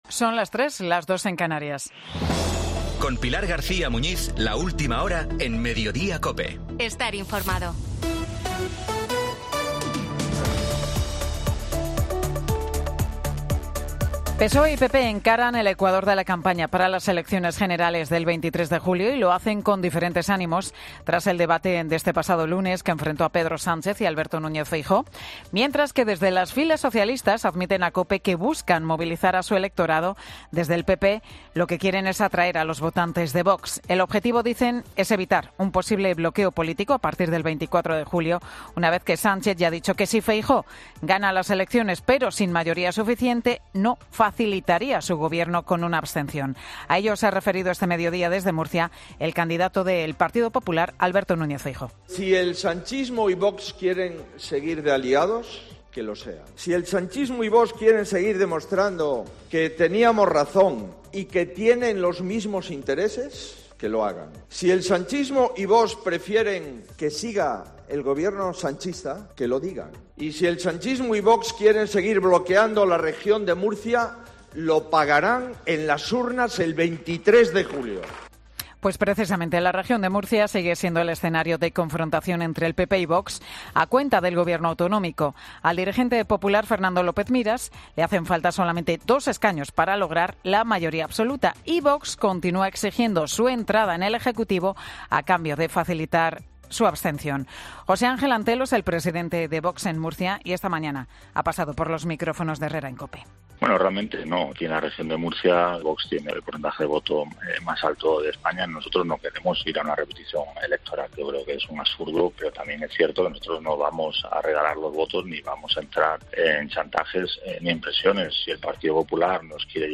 Boletín de noticias de COPE del 12 de julio de 2023 a las 15.00 horas